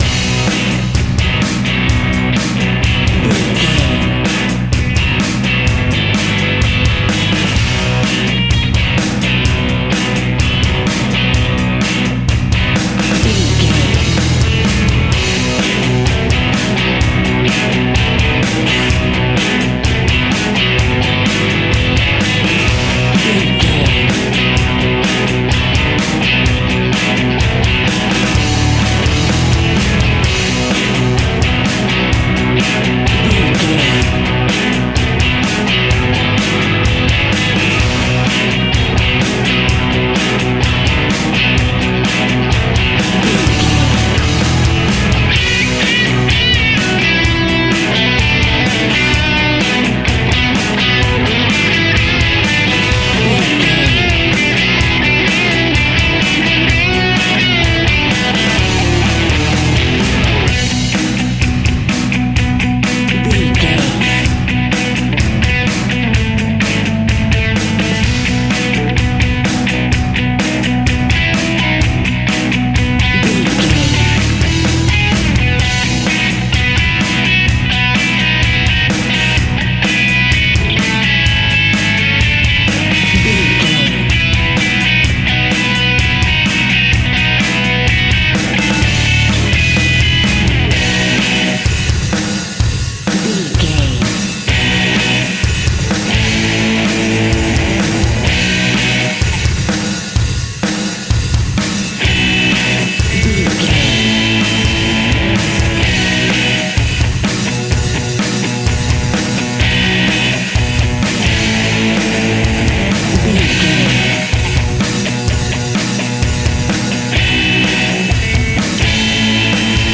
Ionian/Major
energetic
driving
aggressive
electric guitar
bass guitar
drums
heavy metal
heavy rock
distortion
Instrumental rock